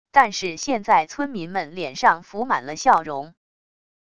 但是现在村民们脸上浮满了笑容wav音频生成系统WAV Audio Player